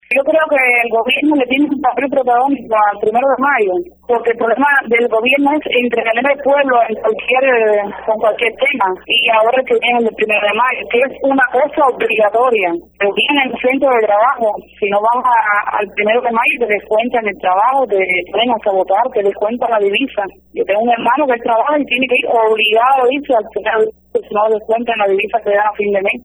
Declaraciones de Cubanos referentes al 1ro de Mayo